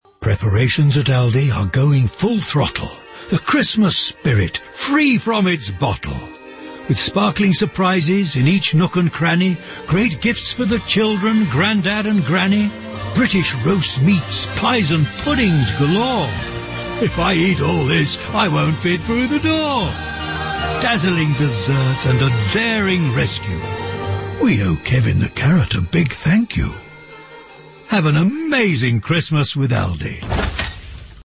Aldi switched focus for the radio ad onto the Christmas delights on offer this season.
Aldi has ensured integration with the wider campaign, with consistent use of music, brand characters, poetic storytelling and voiceover from the inimitable Jim Broadbent.